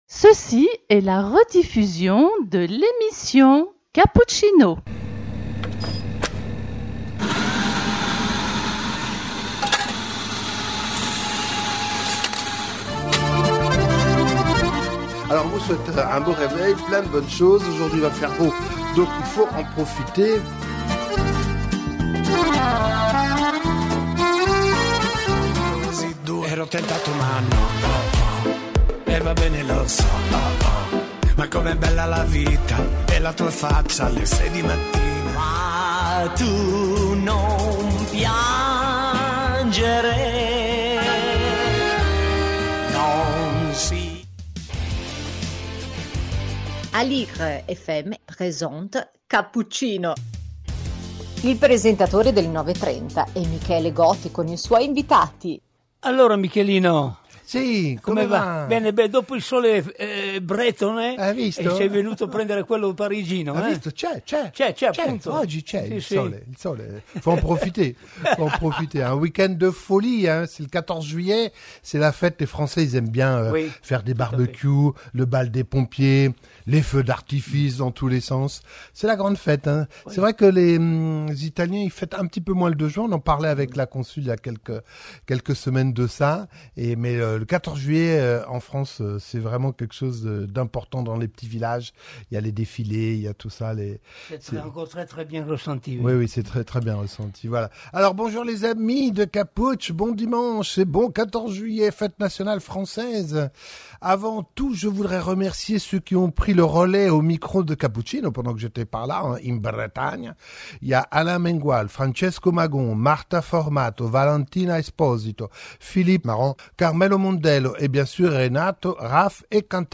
Cappuccino # 14 juillet 2024 - émission en direct avant les rediffusions de l'été